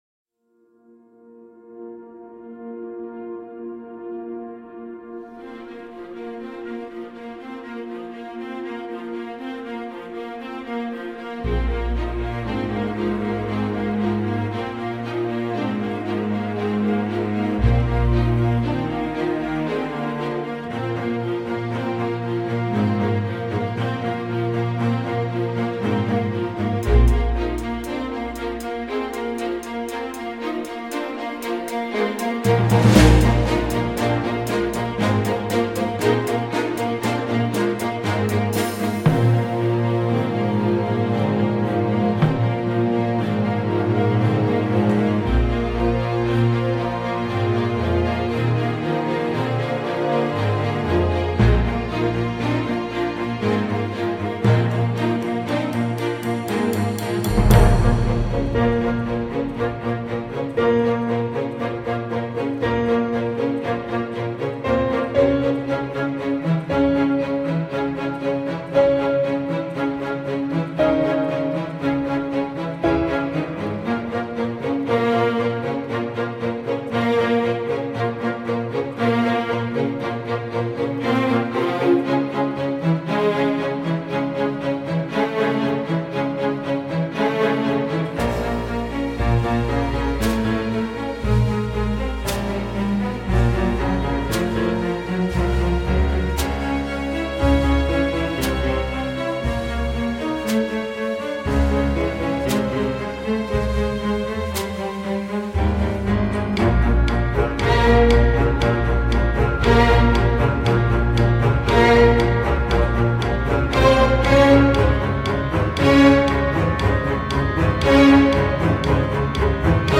cordes